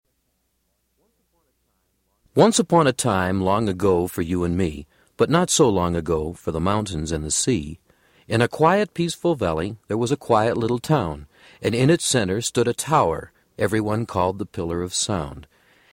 This is a spoken story: